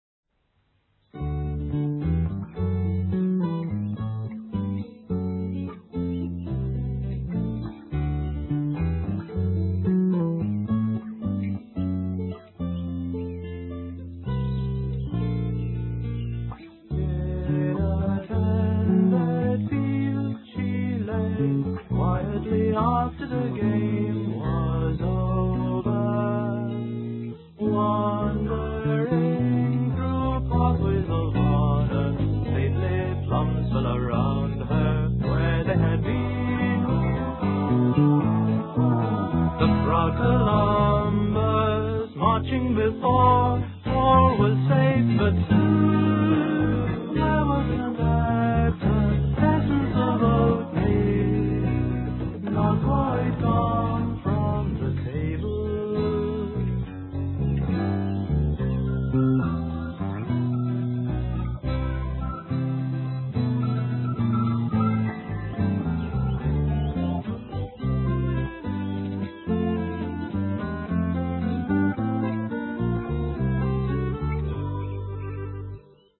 Mono, 1:15, 16 Khz, (file size: 149 Kb).